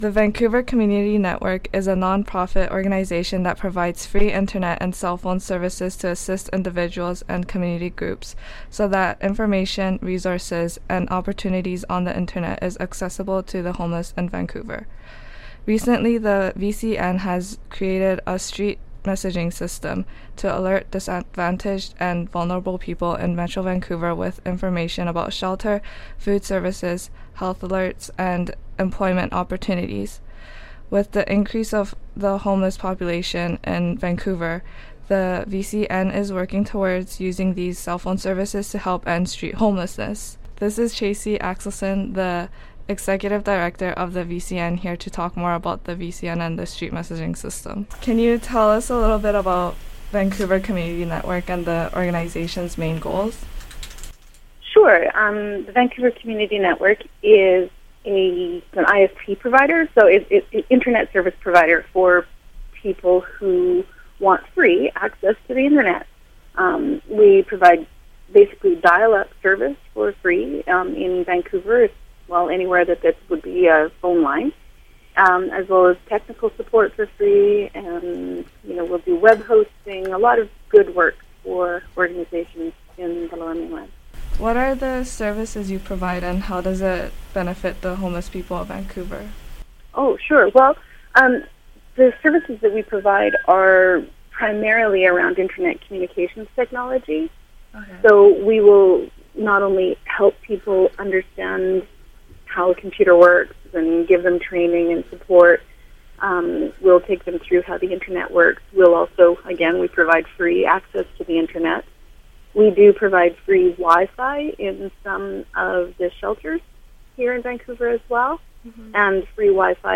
Recording Location: Vancouver, British Columbia
Type: News Reports